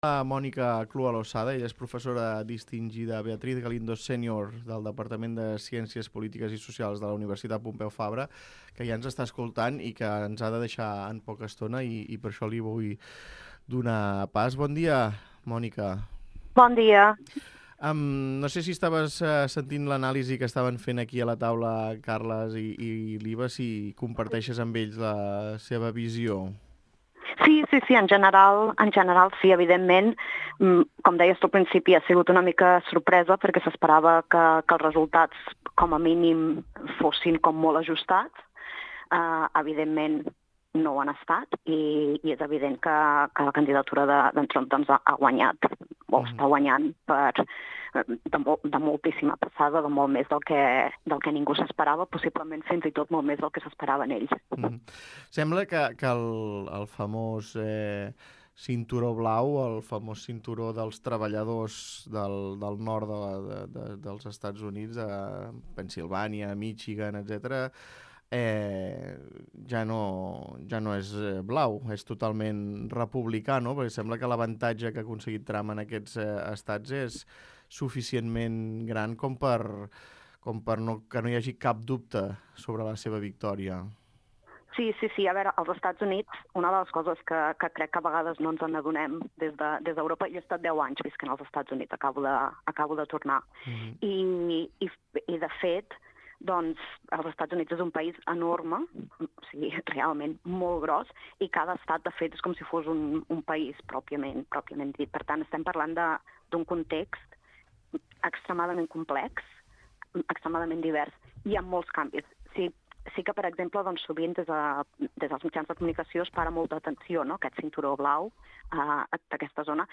Escolta l'entrevista a la professora de ciències polítiques